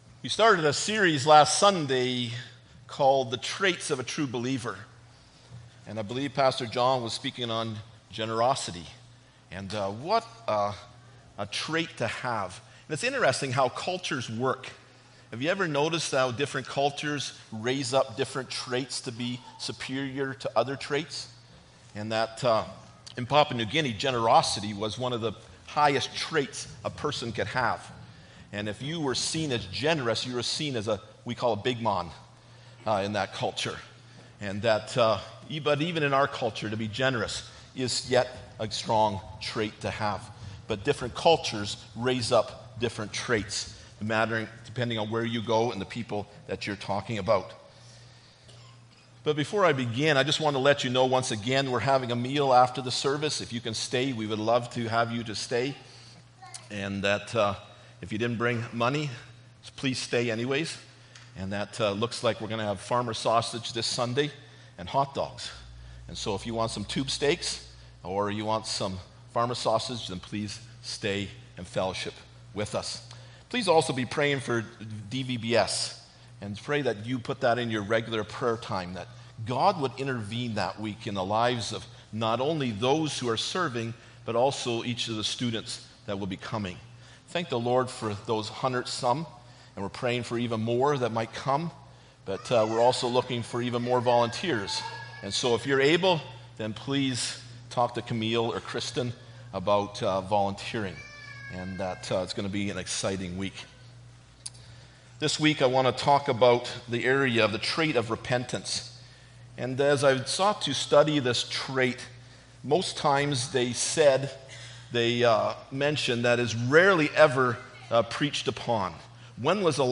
Passage: Mark 1: 4, 14-15 Service Type: Sunday Morning Bible Text